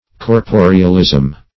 Corporealism \Cor*po"re*al*ism\ (-?z'm), n.